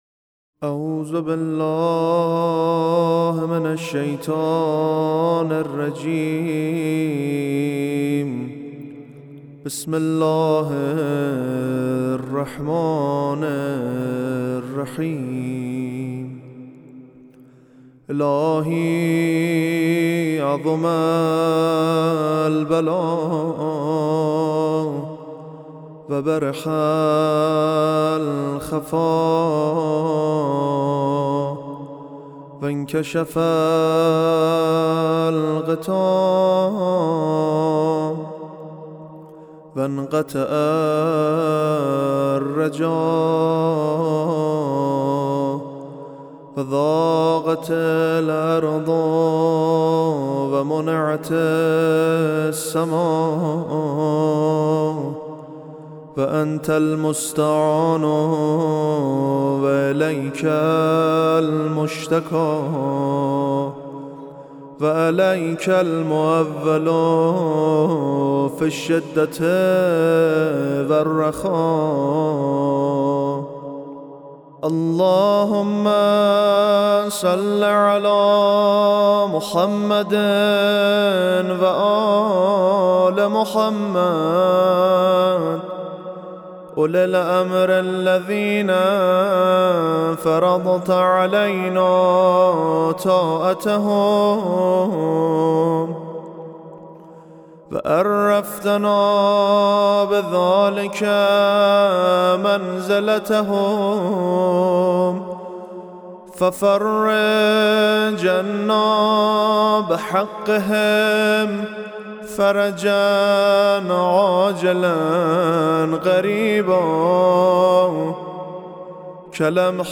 با حال و هوای معنوی و عمیق